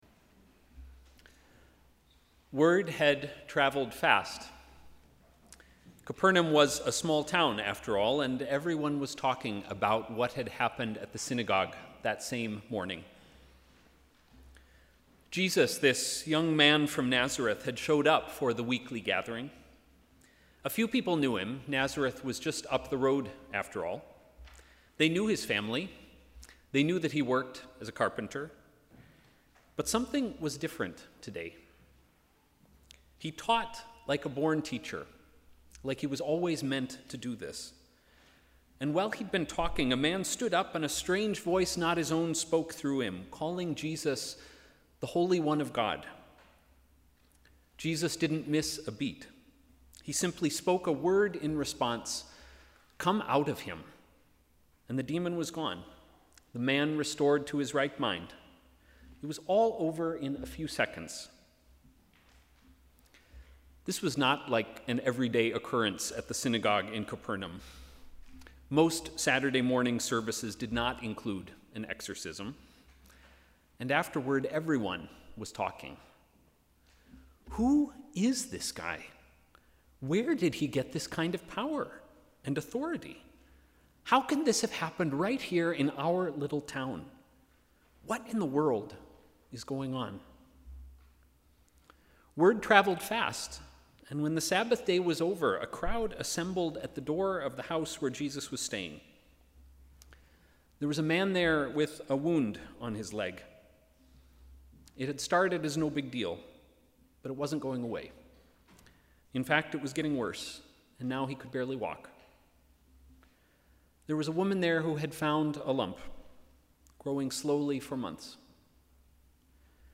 Sermon: ‘Not the only one’
Sermon on the 5th Sunday after Epiphany